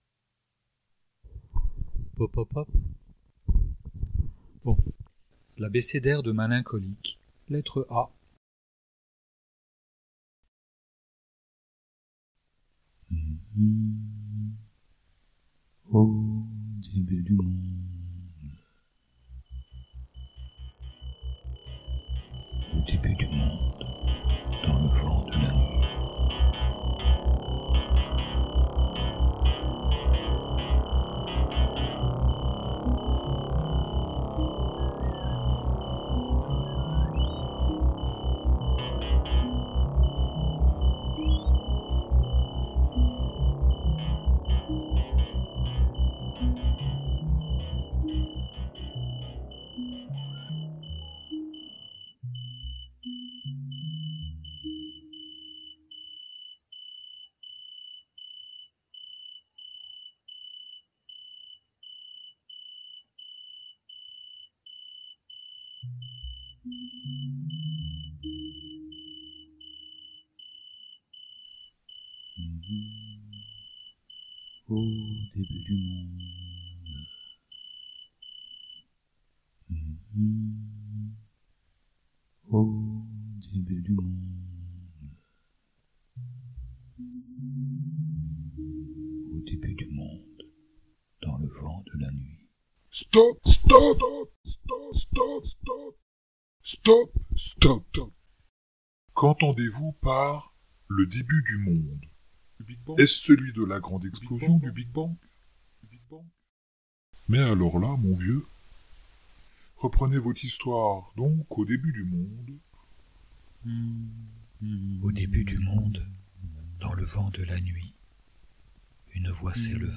UN MONDE BRUISSANT -essais sonores-
Avec les sons j'ai joué, j'insiste sur ce terme, joué comme un bambin sur les touches d'un piano enfantin : une exploration de mon sens auditif ; une expérimentation des outils numériques -simples- glaner par-ci par-là ; des juxtapositions de bruits enregistrés autour de nous.